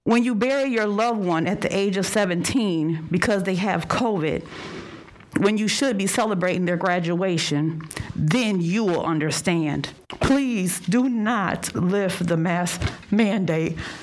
Kalamazoo Public Schools Board of Education meeting March 17, 2022
Several members of the public spoke about masks last night, and all of them were in favor of keeping the mandate.